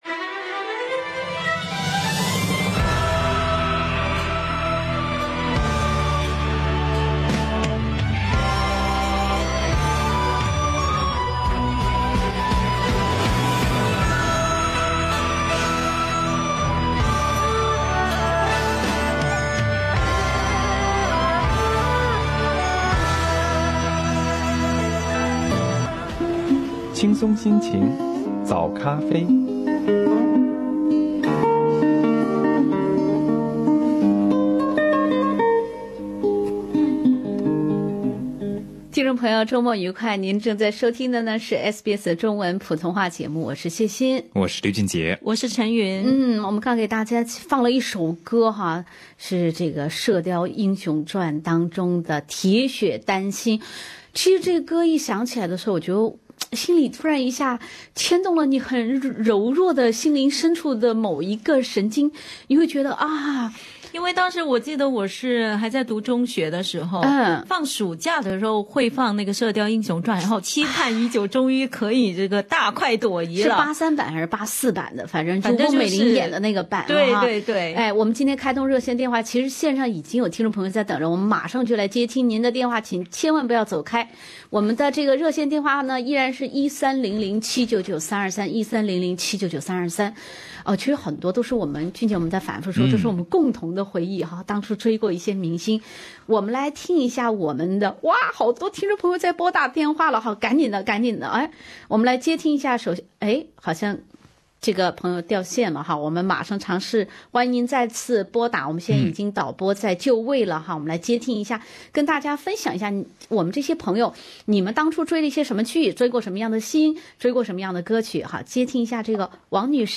在本期早咖啡听众热线特别节目，不少听众朋友回顾了曾经追过的和记忆深刻的香港电影、电视剧、音乐和明星。